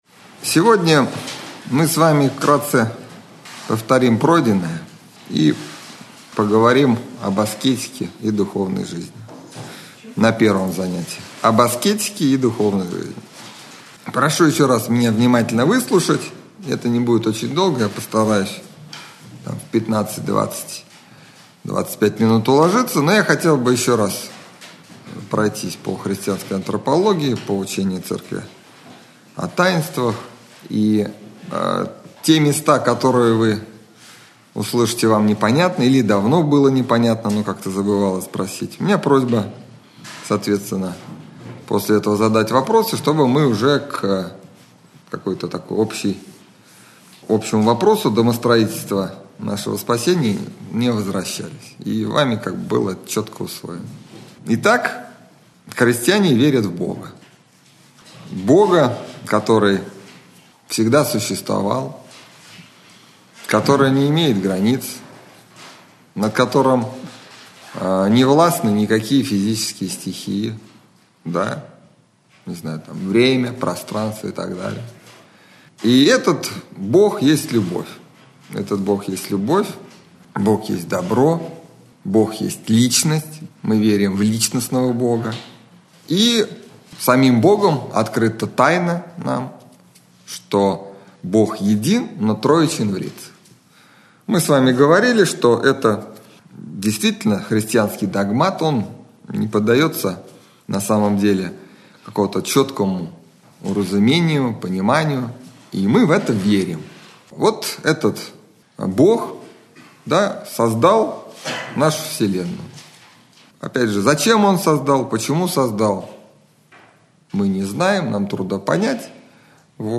лекция
Общедоступный православный лекторий